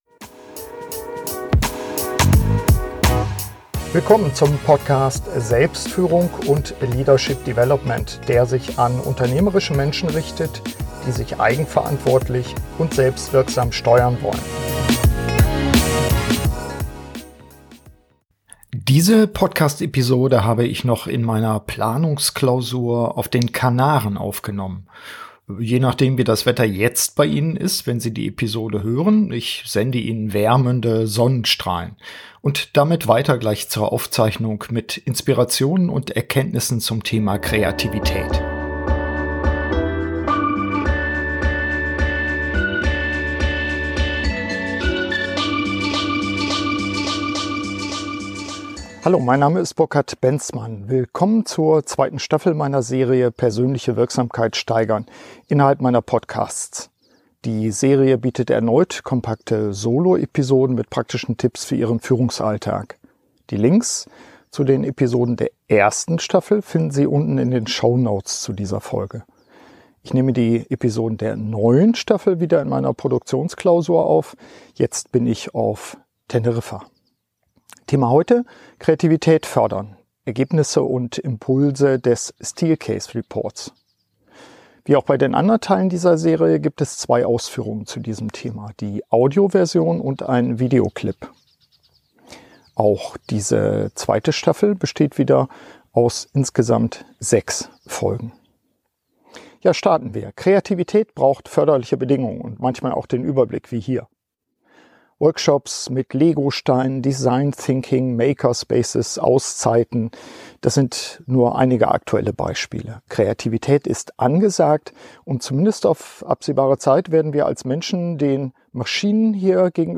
Kreativität verändert sich und damit auch die Anforderungen an uns als Führungskräfte. Der Steelcase-Report zum Thema liefert wichtige Erkenntnisse, die ich in dieser Podcast-Soloepisode mit meinen eigenen Tipps zur Förderung der Kreativität abrunde.